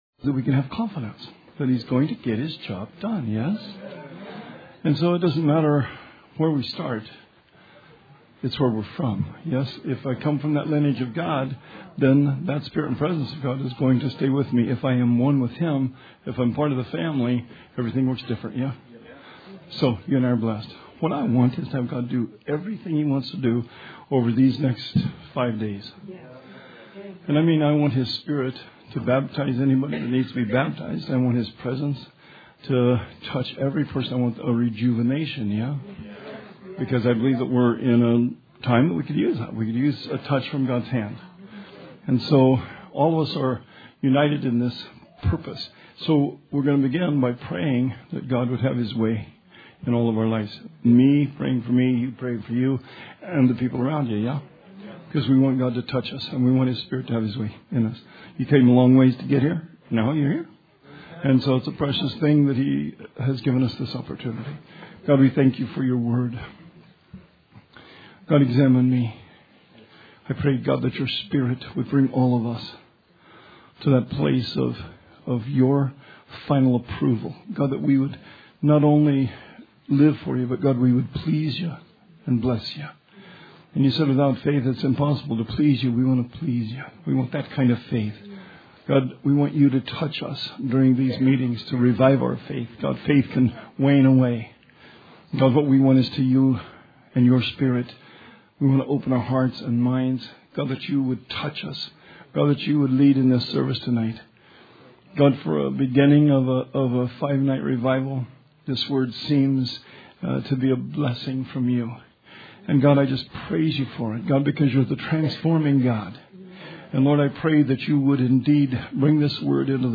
Sermon 12/27/19